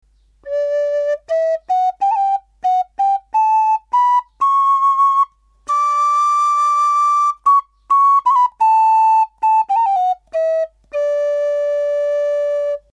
ひのきのたて笛
ひのきでつくられています　やわらかな音色です